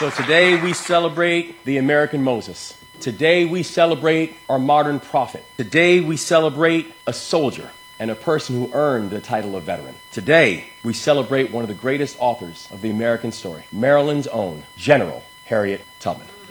During a special ceremony on Veterans Day, Maryland Governor Wes Moore gave the posthumous commission of Brigadier General to Harriett Tubman for her military service to the United States and Maryland.